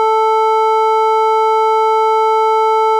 OPL waveforms
On/off sine – every other cycle only. Waveform 4. Scaling PD variation. Zoom out 2x.